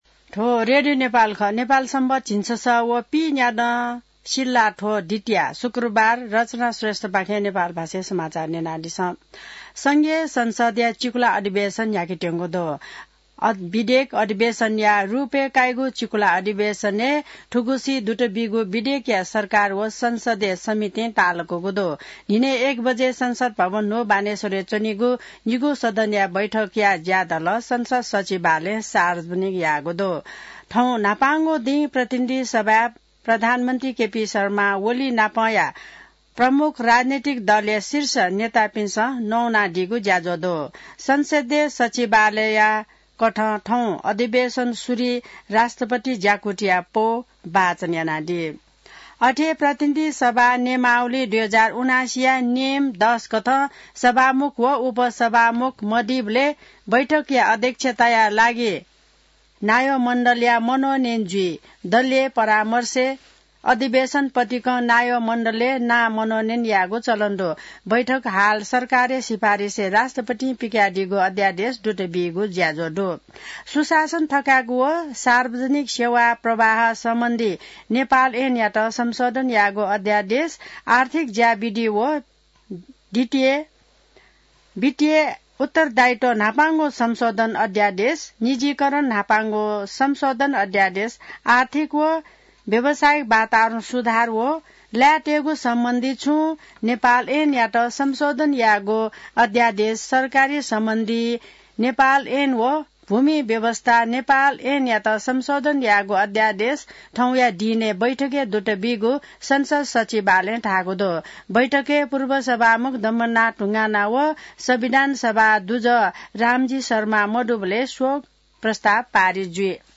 नेपाल भाषामा समाचार : १९ माघ , २०८१